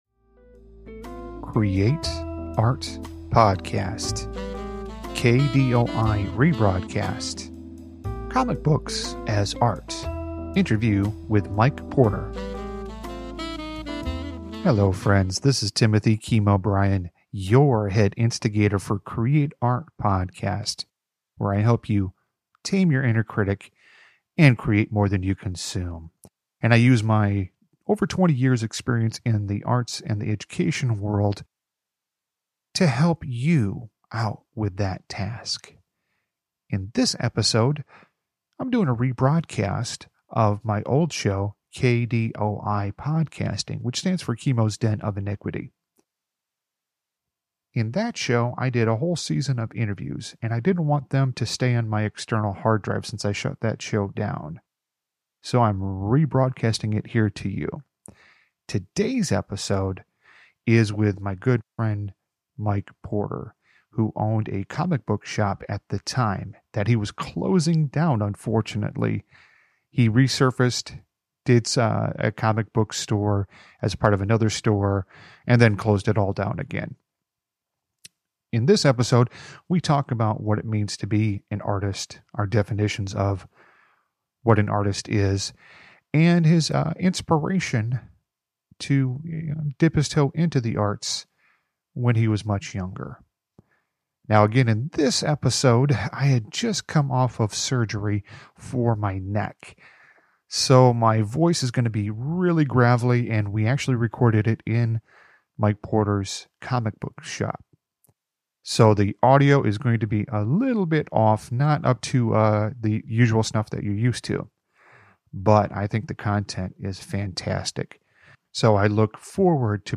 I had just recovered from neck surgery so my voice is still gravely in this episode. We discuss his thoughts on art and what it means to be an artist. We also dive into his exploits in the comic book world and future projects he would like to accomplish.